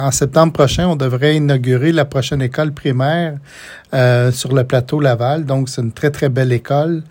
En entrevue, le député de Nicolet-Bécancour a mentionné qu’il travaillait toujours sur ce dossier.